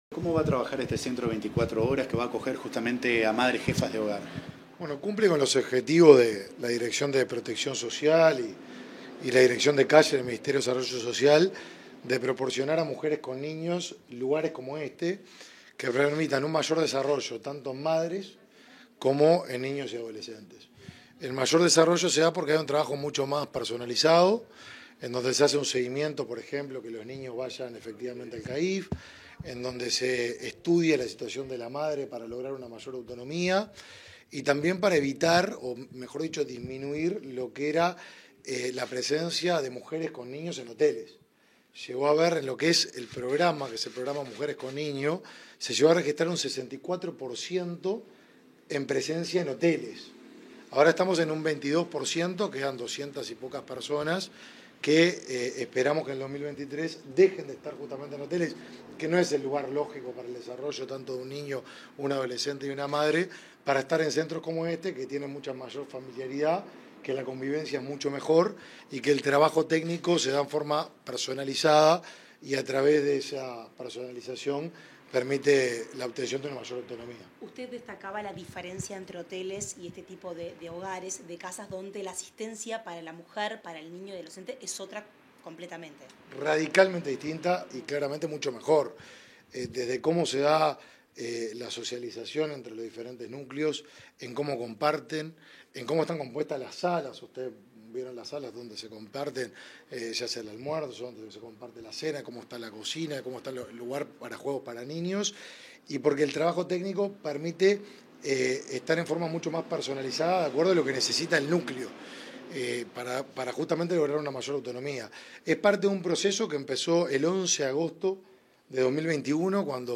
Declaraciones del ministro de Desarrollo Social, Martín Lema
Declaraciones del ministro de Desarrollo Social, Martín Lema 22/02/2023 Compartir Facebook X Copiar enlace WhatsApp LinkedIn Tras inaugurar un nuevo centro 24 horas para familias monoparentales, este 22 de febrero, el ministro de Desarrollo Social, Martín Lema, realizó declaraciones a la prensa.